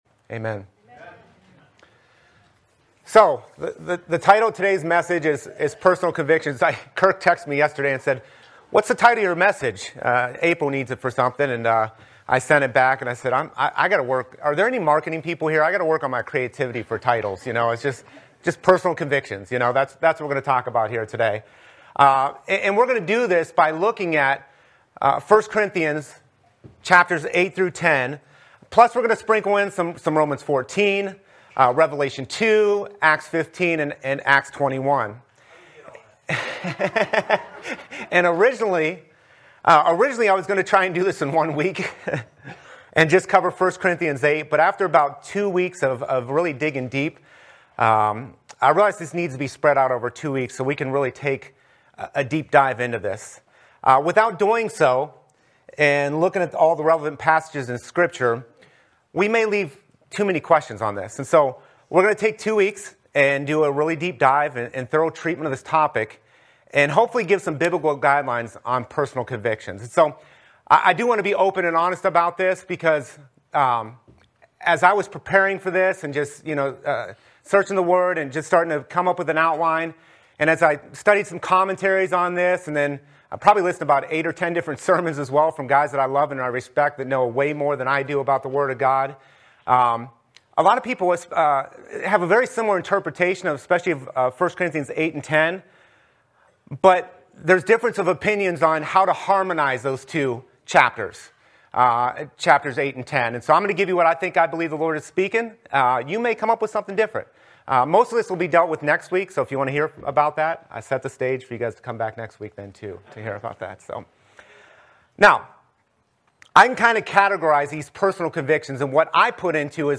From Series: "Sunday Morning Service"